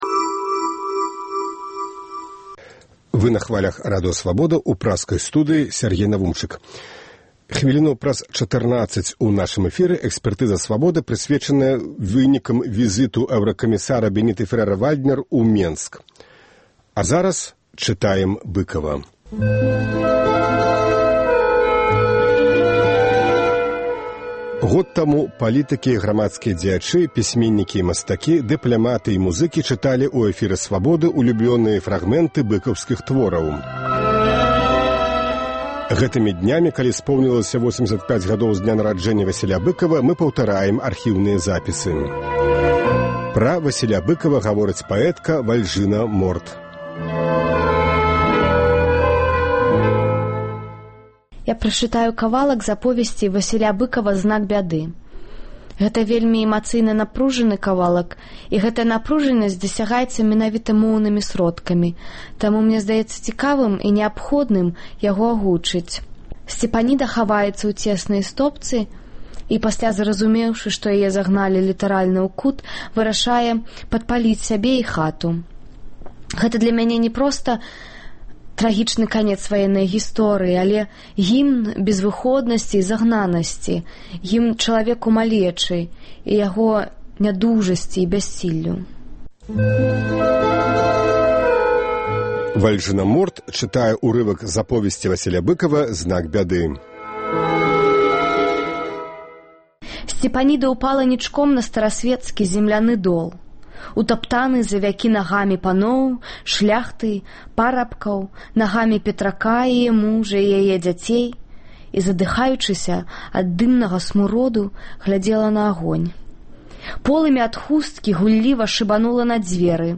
Год таму палітыкі і грамадзкія дзеячы, пісьменьнікі і мастакі, дыпляматы і музыкі чыталі ў эфіры Свабоды ўлюблёныя фрагмэнты быкаўскіх твораў. Гэтымі днямі, калі споўнілася 85-гадоў з дня нараджэньня Васіля Быкава, мы паўтараем архіўныя запісы. Сёньня Быкава чытаюць паэтка Вальжына Морт і паэт Уладзімер Някляеў.